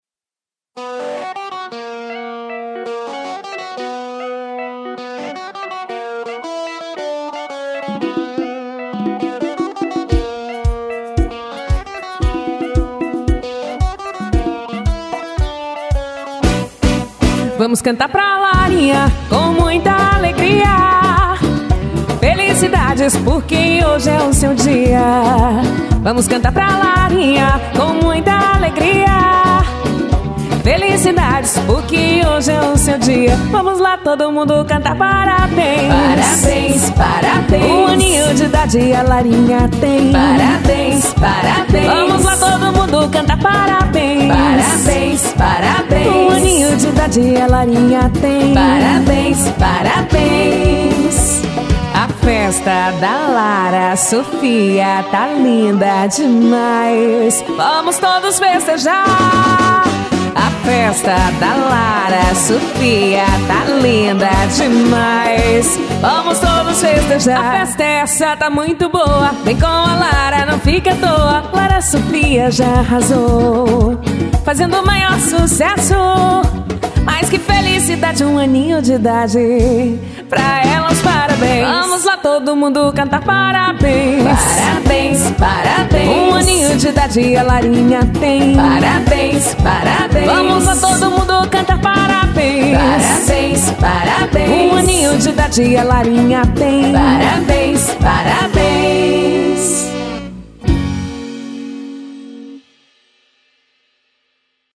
Axé